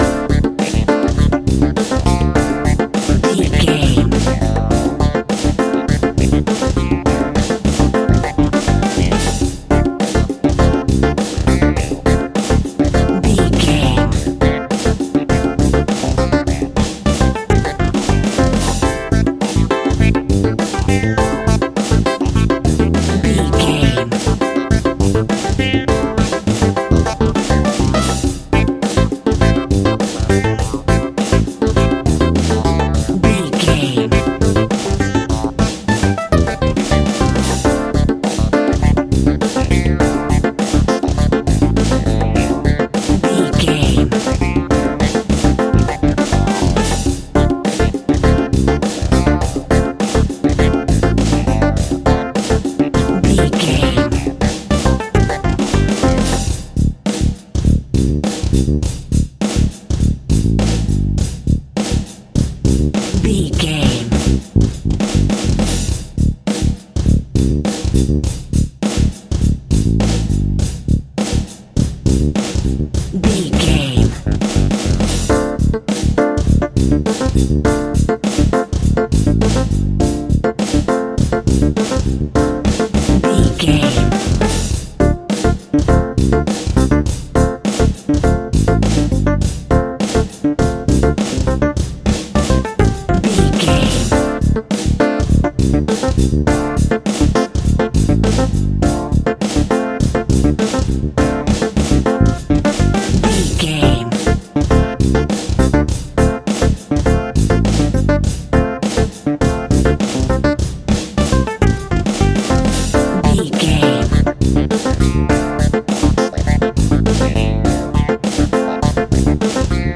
Aeolian/Minor
disco funk
soul jazz
electric guitar
bass guitar
drums
hammond organ
fender rhodes
percussion